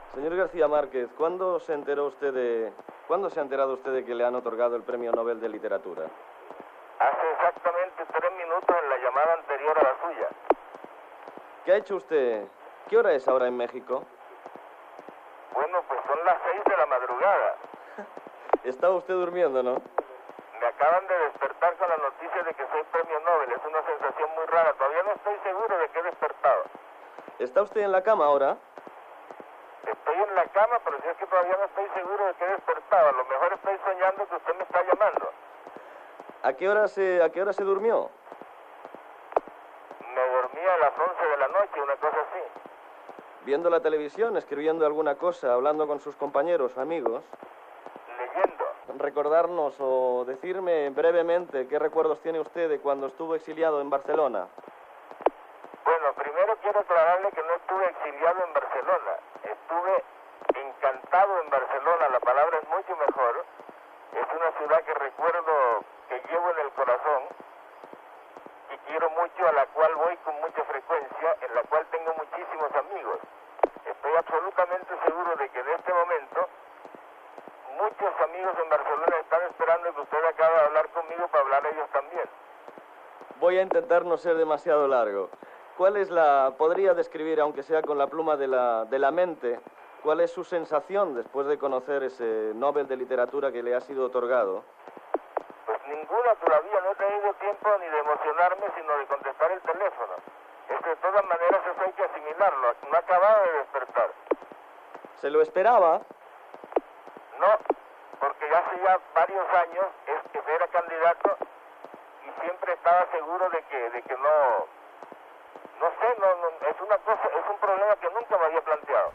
Entrevista telefònica a l'escriptor Gabriel García Márquez, que està a Mèxic, i li acaben de comunicar que rebrà el Premi Nobel de Literatura. García Márquez recorda la seva estada a Barcelona
Informatiu